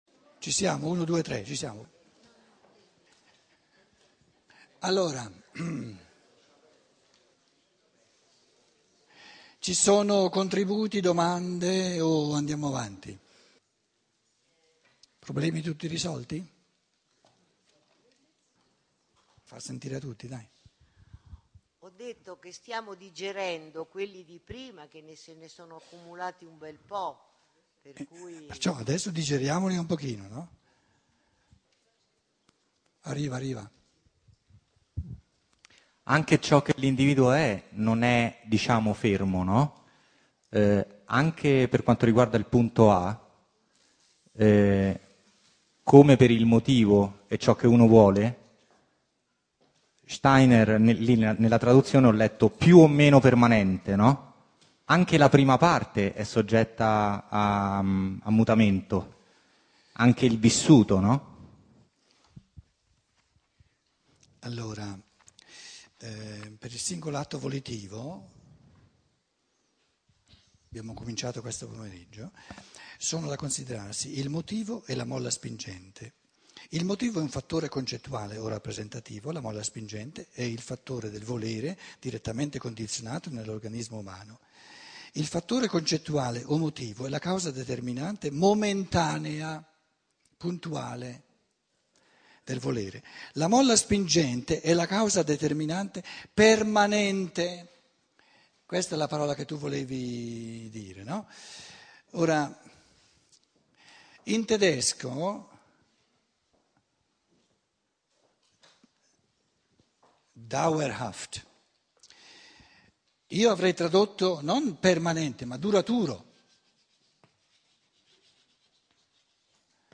06E - Sesta conferenza - sabato pomeriggio